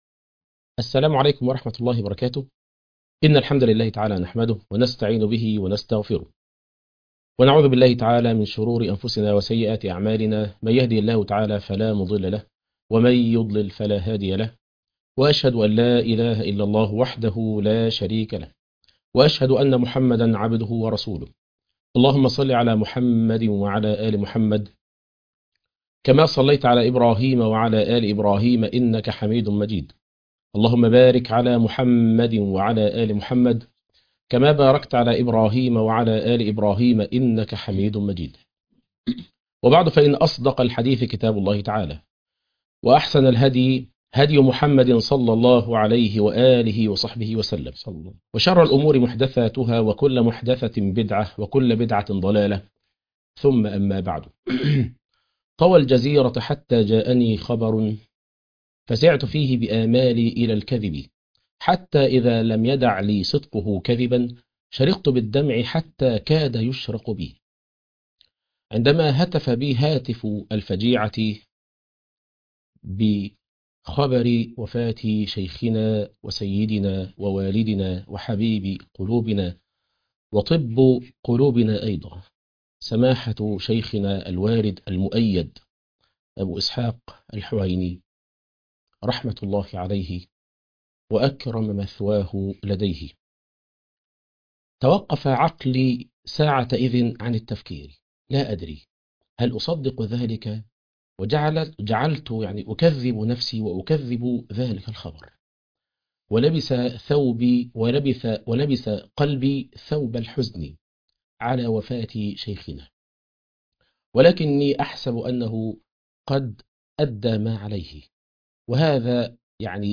الشيخ الحويني ومكانة العلماء _ تأبين الشيخ الحويني _ مع نخبة من العلماء والدعاة - قسم المنوعات